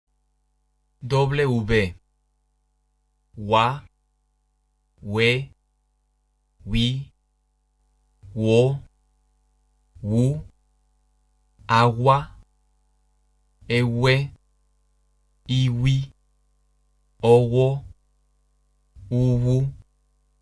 W发音：
w的发音与b相同，但只用来拼写外来语